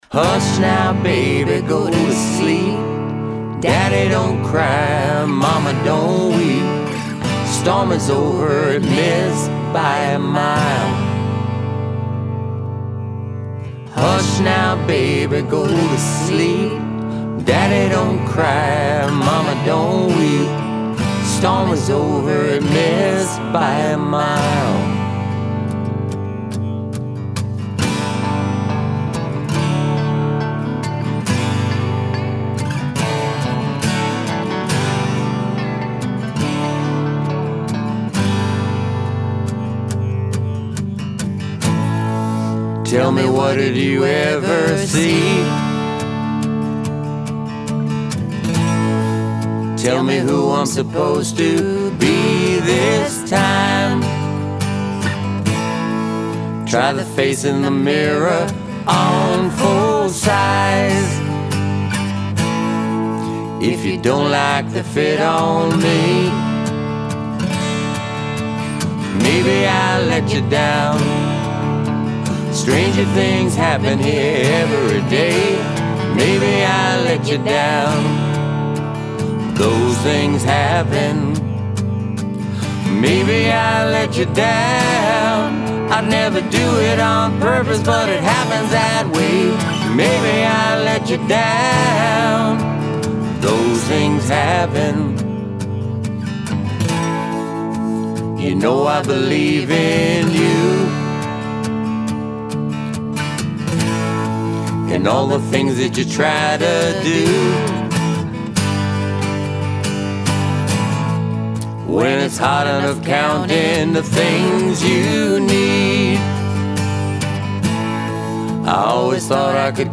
Vocals
Guitars and Vocals